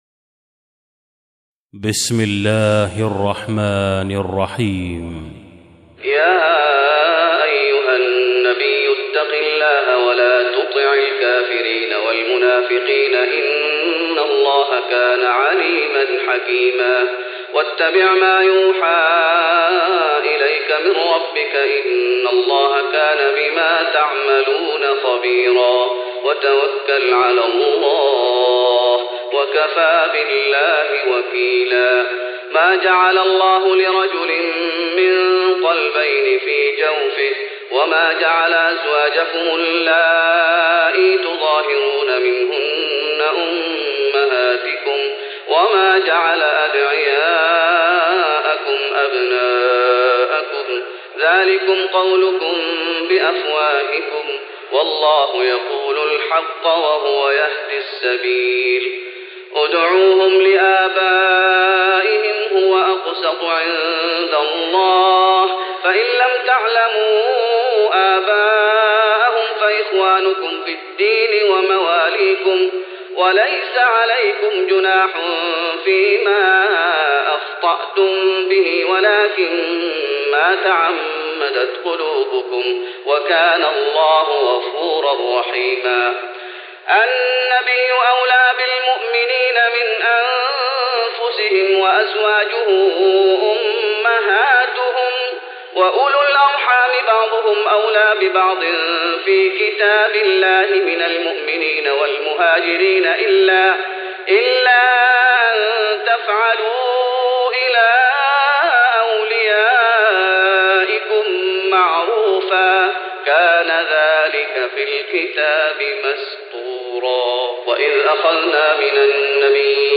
تراويح رمضان 1415هـ من سورة الأحزاب (1-58) Taraweeh Ramadan 1415H from Surah Al-Ahzaab > تراويح الشيخ محمد أيوب بالنبوي 1415 🕌 > التراويح - تلاوات الحرمين